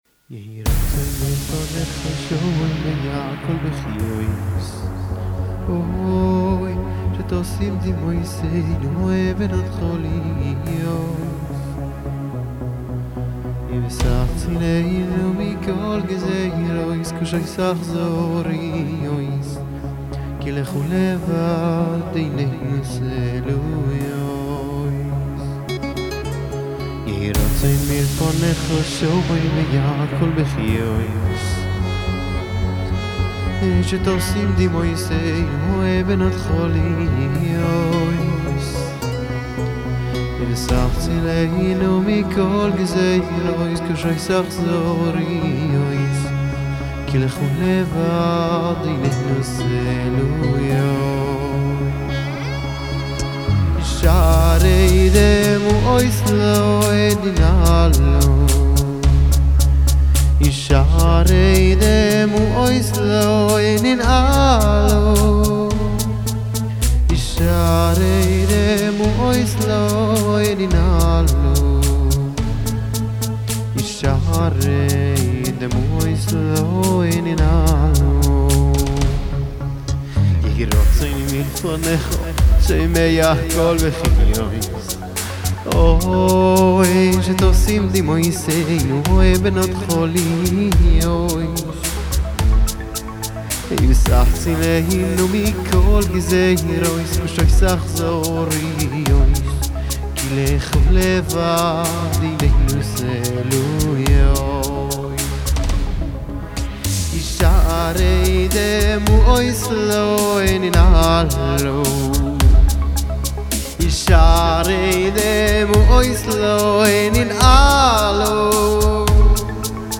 ביצוע לייב שלי (שירה ונגינה)